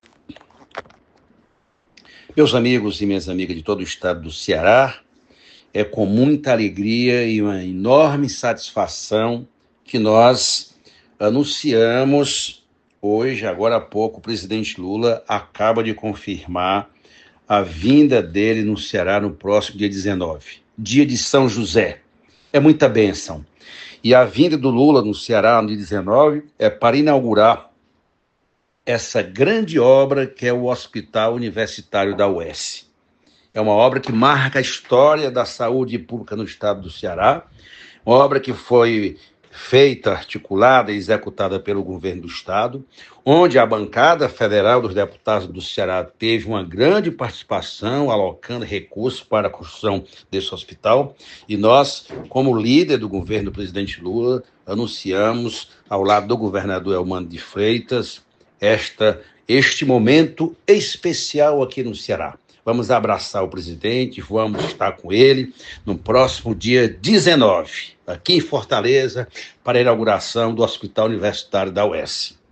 José Guimarães falou ao programa, destacando o trabalho da bancada cearense para a finalização da obra, confira.